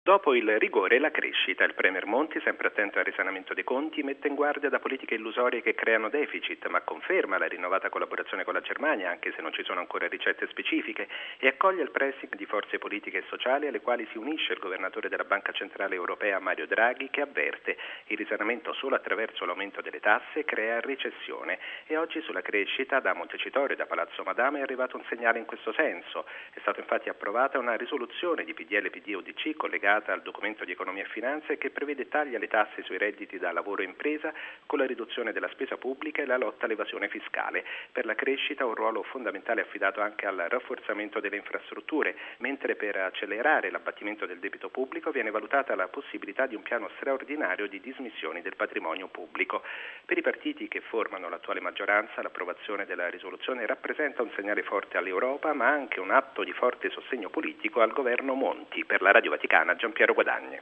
Servizio